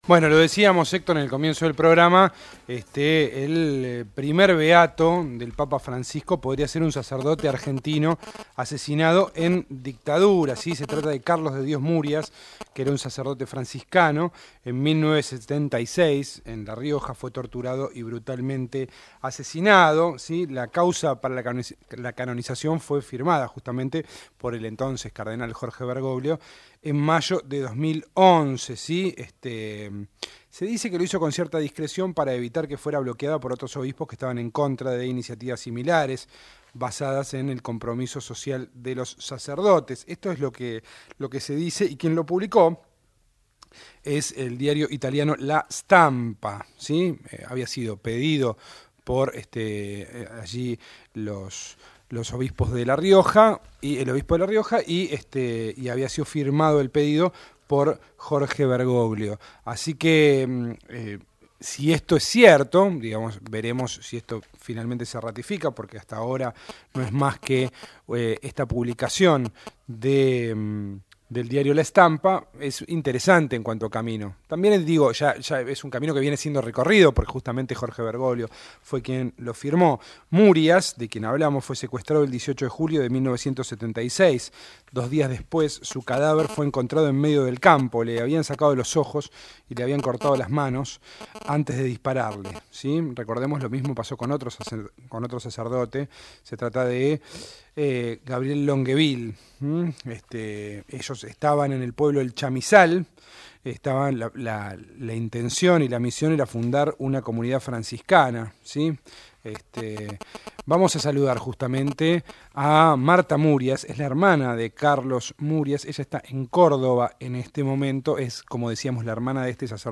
por Radio Nacional